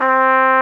Index of /90_sSampleCDs/Roland LCDP12 Solo Brass/BRS_Trumpet 5-7/BRS_Tp 7 Warm